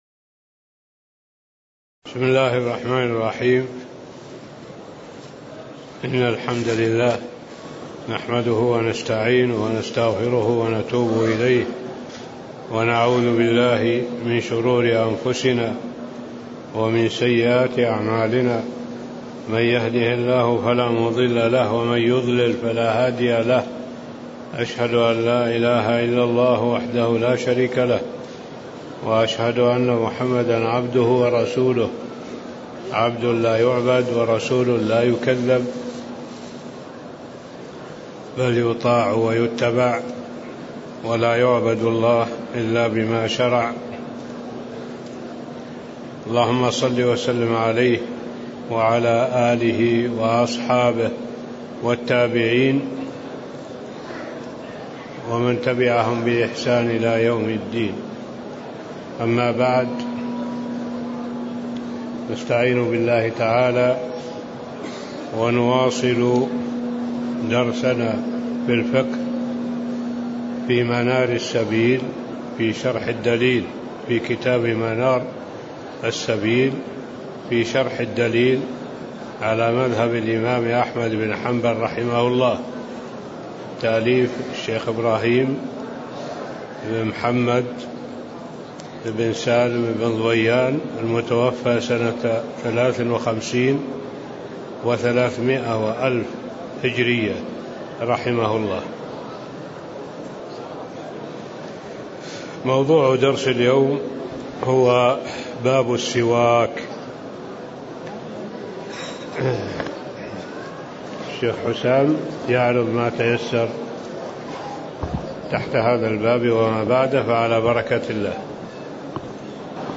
تاريخ النشر ١٨ جمادى الأولى ١٤٣٦ هـ المكان: المسجد النبوي الشيخ